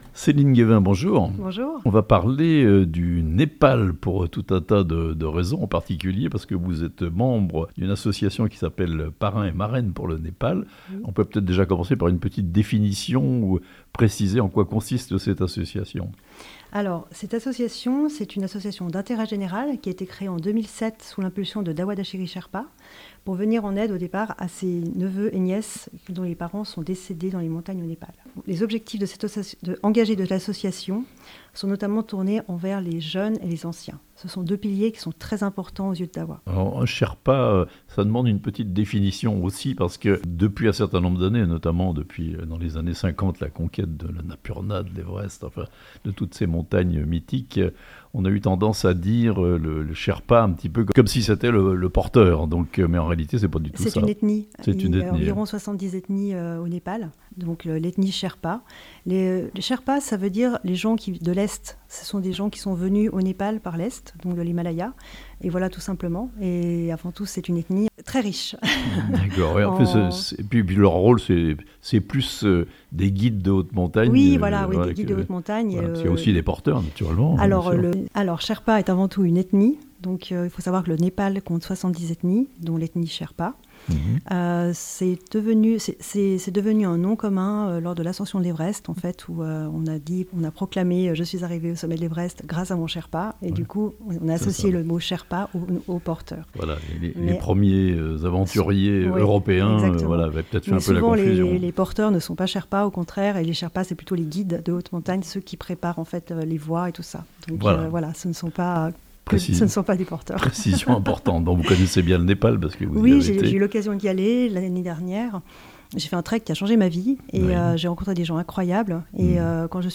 Une association locale pour venir en aide aux populations du Népal (interview)